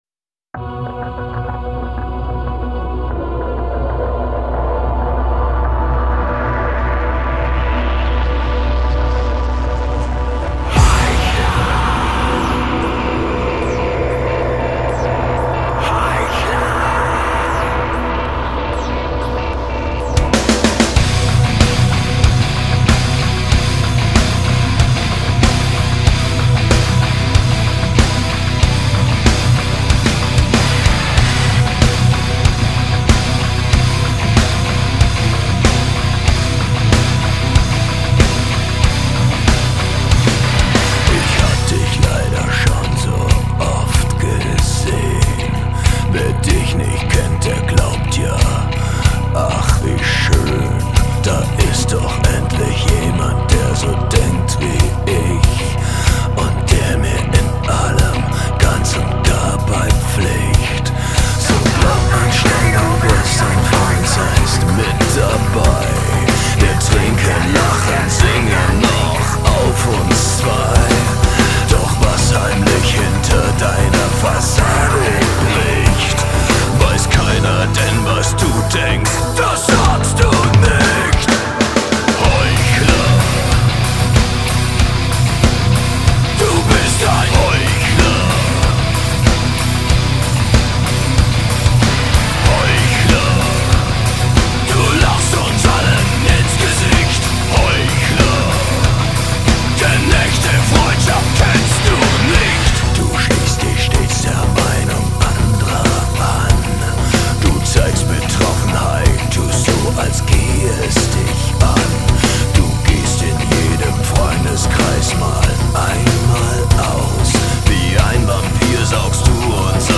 Жанр: Industrial, Alternative